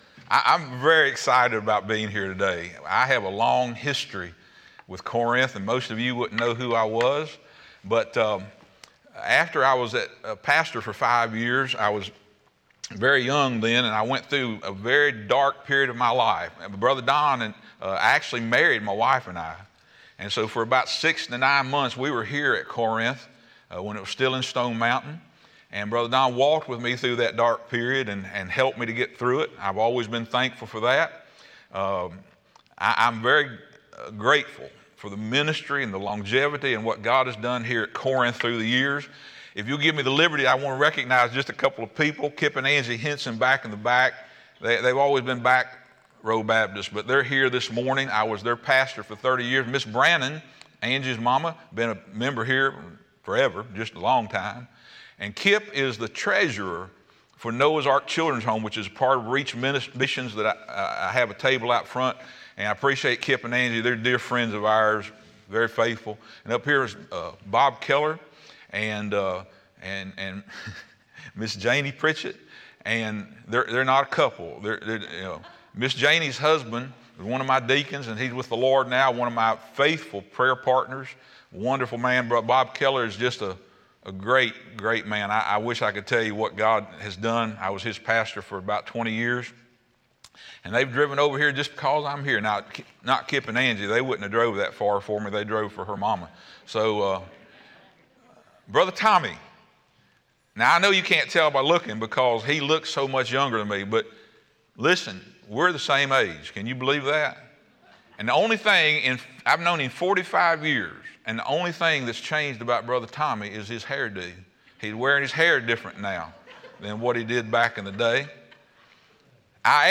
Speaker: Missionary